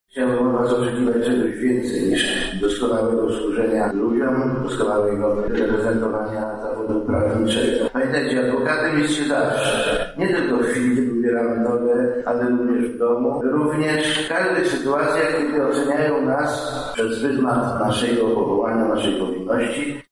26 lipca w Auli Collegium Maius Uniwersytetu Medycznego 62 młodych adwokatów złożyło ślubowanie.